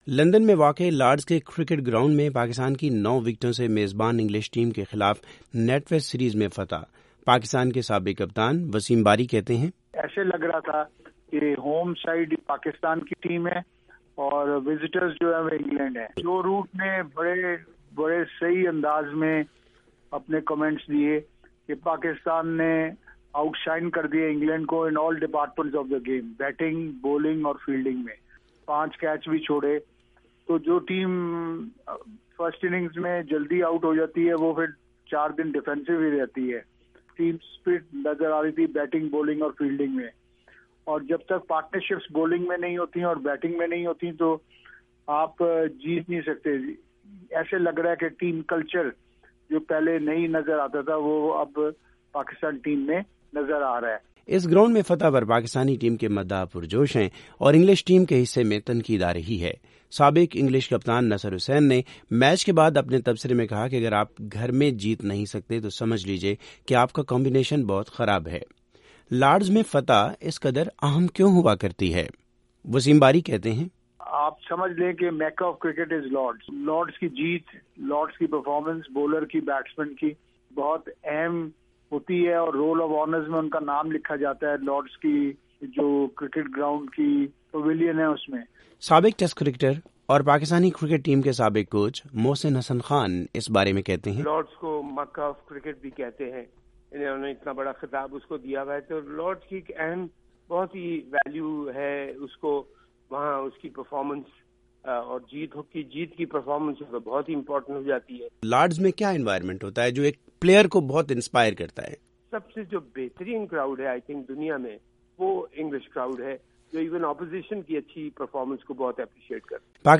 پاکستان کے سابق کپتان وسیم باری وائس آف امریکہ سے گفتگو میں کہتے ہیں،
وسیم باری اور محسن حسن خان کی رائے سننے کیلئے اس لنگ پر جائیں: